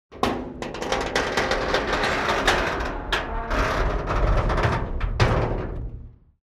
shipping_container.ogg